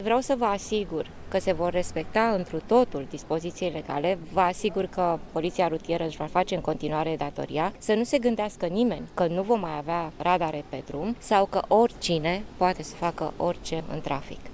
Tot azi, ministrul de Interne, Carmen Dan, a dat asigurări că poliţiştii rutieri şi vor face datoria în continuare şi a reamintit că Guvernul a dat aviz negativ acestor modificări: